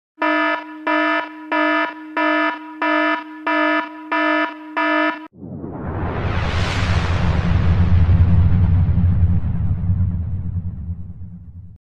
Sonido-de-alerta.mp3
KGmpytxUfAk_Sonido-de-alerta.mp3